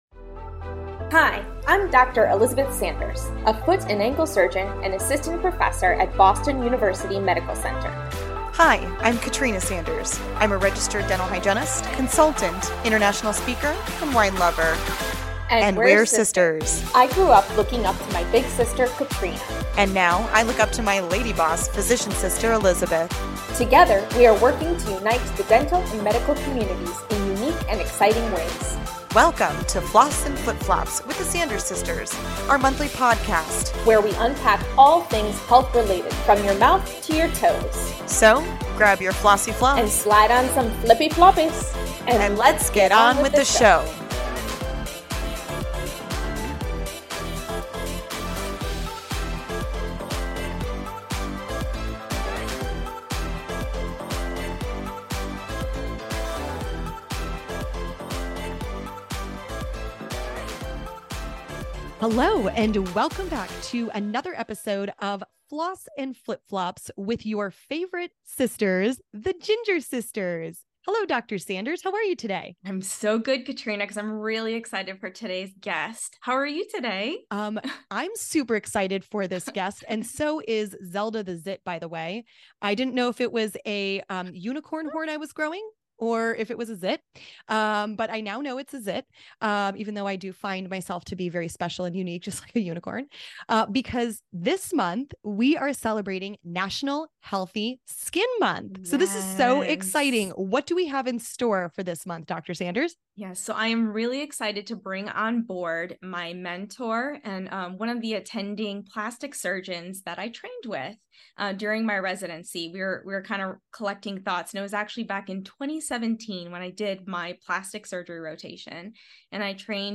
Together, the sisters discuss the oral-systemic link and its impact—from your teeth down to your toes.